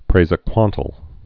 (prāzə-kwŏntl)